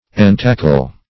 Entackle \En*tac"kle\, v. t. To supply with tackle.